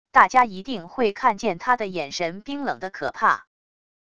大家一定会看见他的眼神冰冷的可怕wav音频生成系统WAV Audio Player